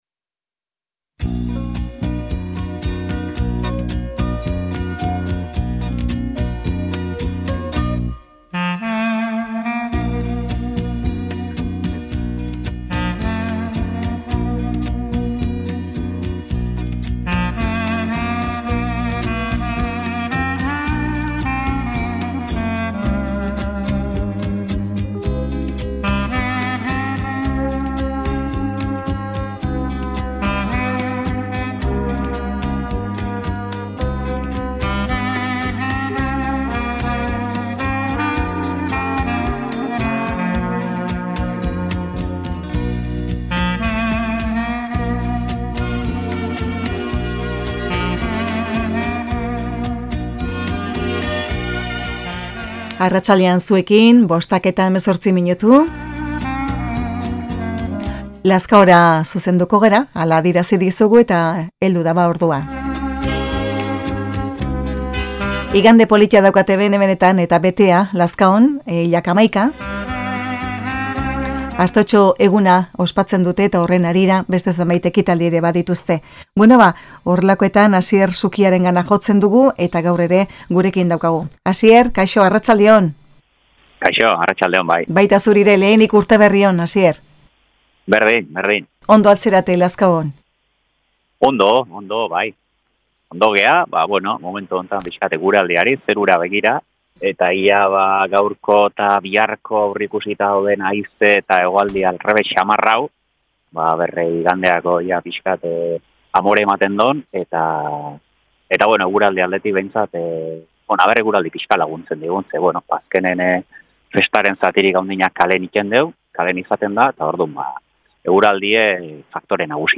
Alkarrizketa Egiptoko ihesa 26-1-9 Reproducir episodio Pausar episodio Mute/Unmute Episode Rebobinar 10 segundos 1x Fast Forward 30 seconds 00:00 / 28:42 Suscribir Compartir Feed RSS Compartir Enlace Incrustar